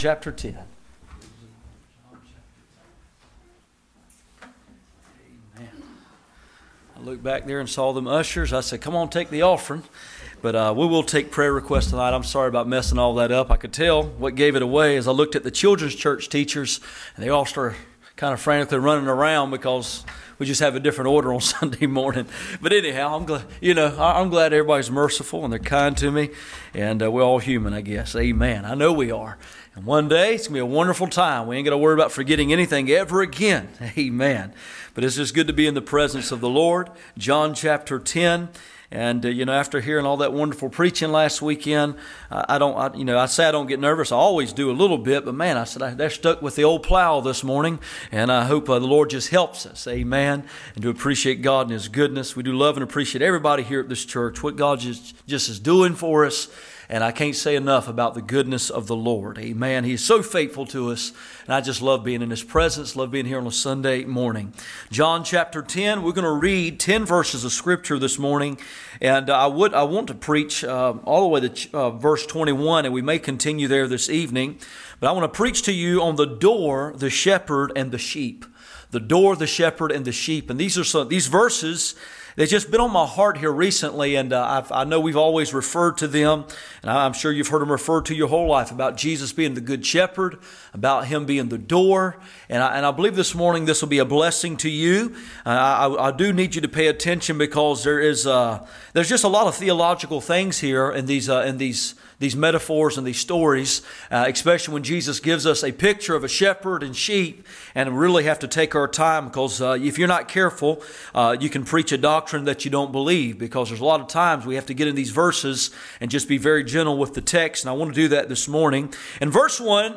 Passage: John 10:1-10 Service Type: Sunday Morning %todo_render% « Broken in all the right places The Door